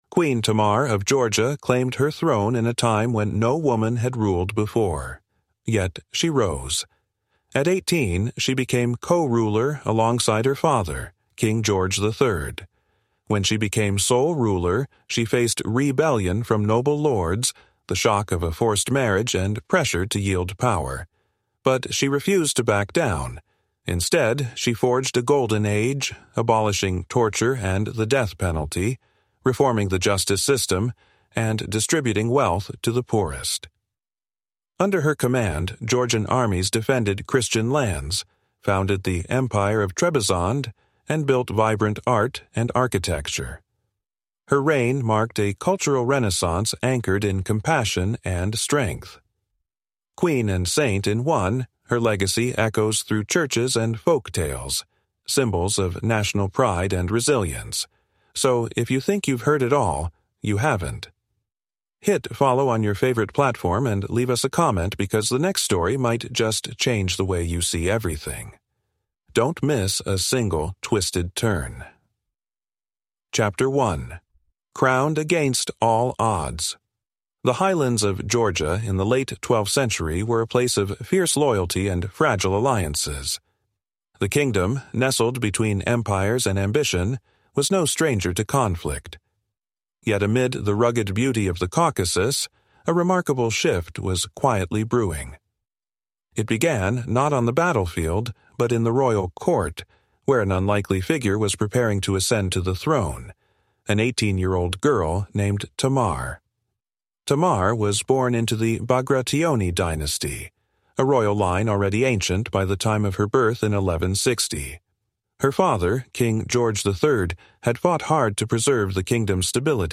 Queen Tamar of Georgia: Resilience and Legacy in History is a powerful, cinematic seven-part documentary that transports you into the life of one of the most iconic female monarchs in world history. Journey through the rise of Queen Tamar as she defies patriarchy, leads her kingdom into a Golden Age, and builds a legacy grounded in justice, cultural pride, and spiritual power.